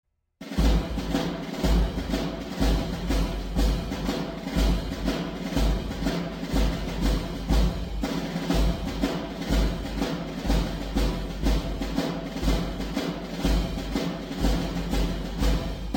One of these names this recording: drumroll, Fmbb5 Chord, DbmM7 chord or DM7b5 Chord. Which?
drumroll